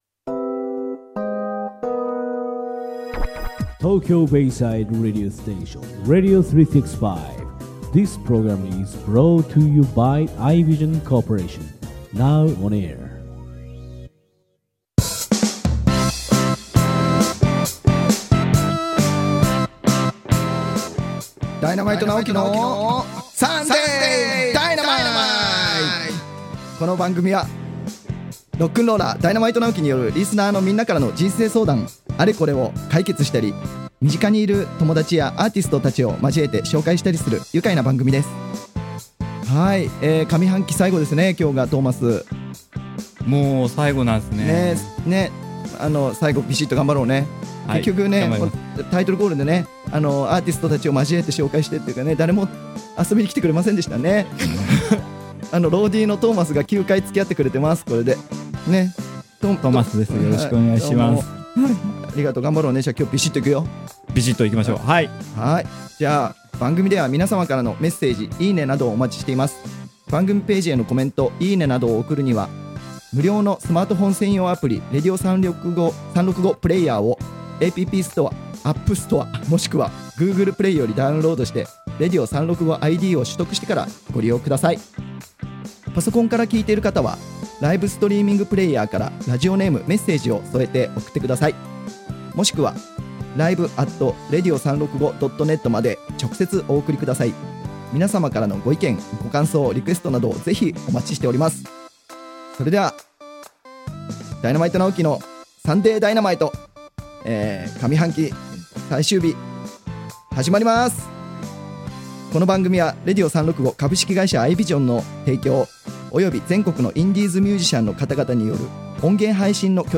【この音源は生放送のアーカイブ音源となります。】